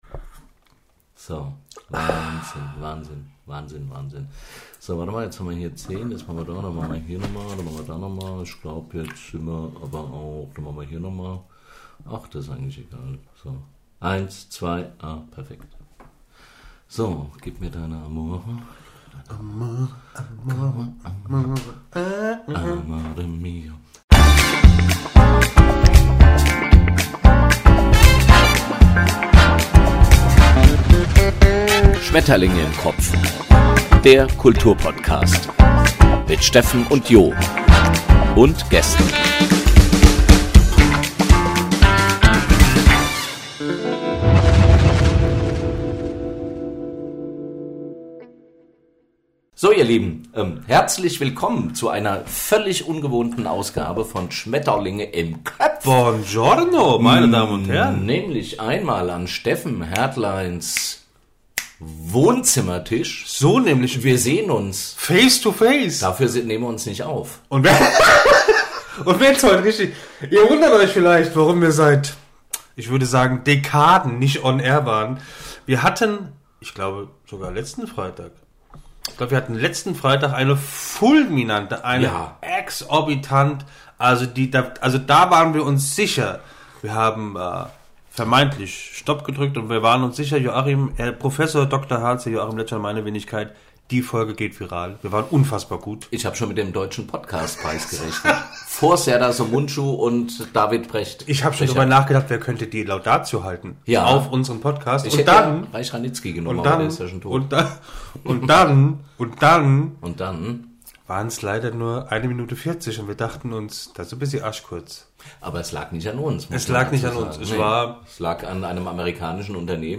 141_Schmetterlinge_im_kopf_noisy.mp3